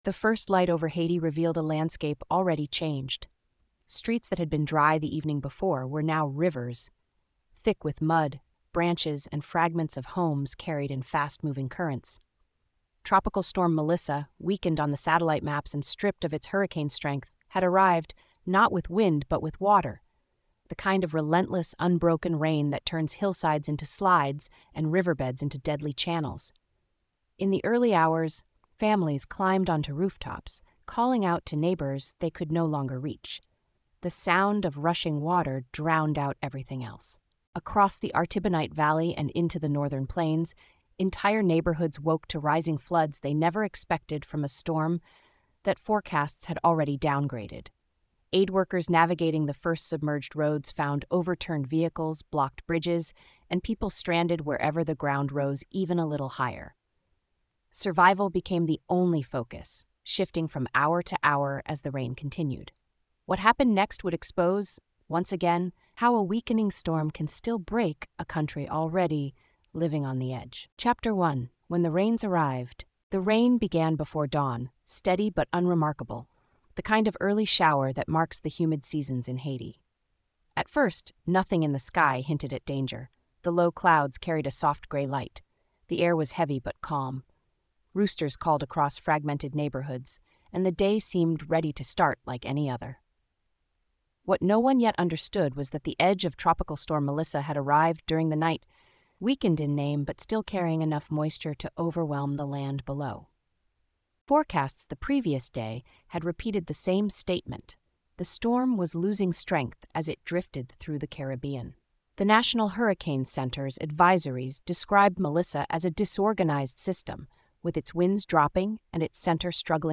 This documentary episode explores the deadly sweep of Tropical Storm Melissa as it weakened over the Caribbean yet unleashed catastrophic flash floods across northern Haiti. Told in a grounded, fact-checkable, cultural-history style, the story opens with a chilling dawn scene: calm streets slowly transforming into rivers as the storm’s rain bands settle over the country.